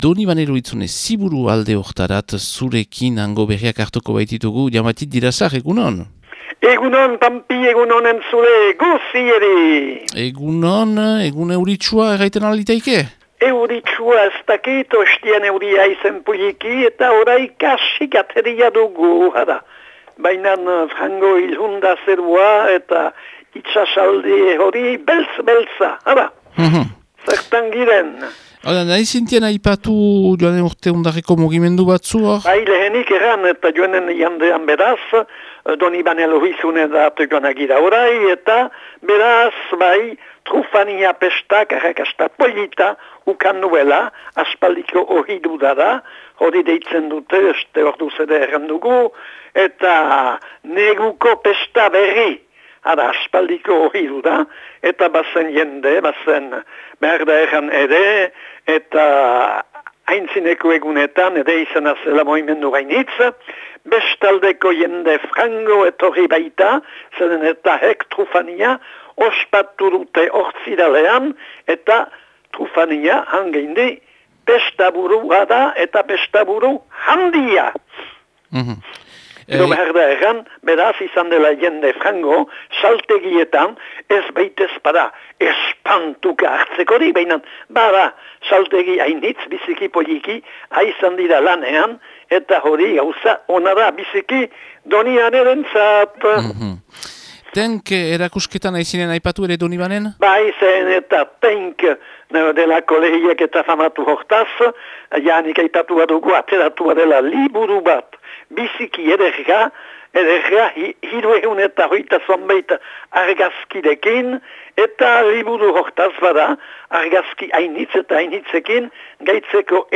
Urtarilaren 13ko Donibane Lohizune eta Ziburuko berriak